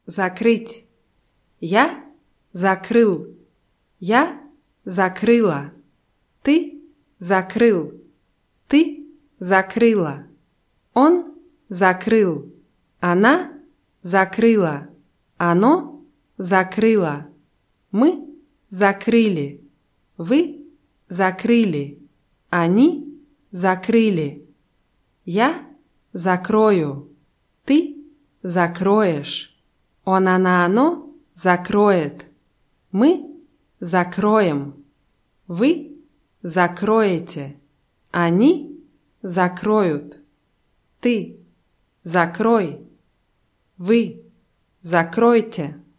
закрыть [zakrýtʲ]